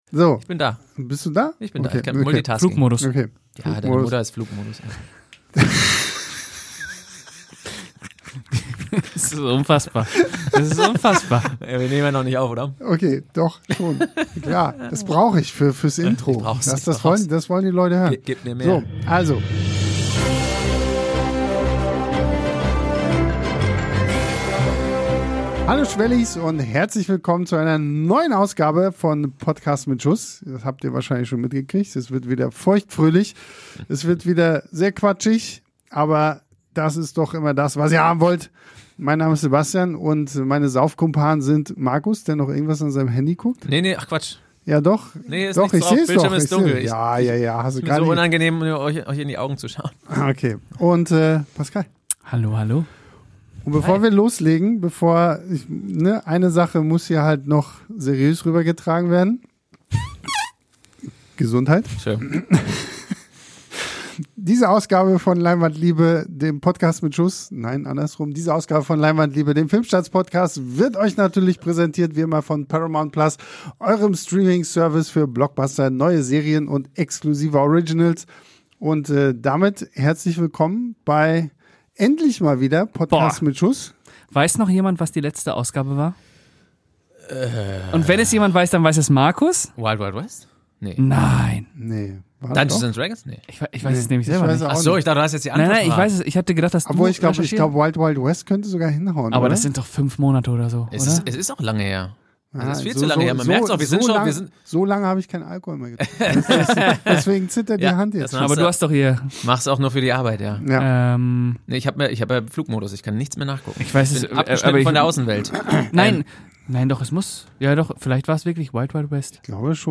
Ohne Skript, aber mit Liebe und Fachwissen.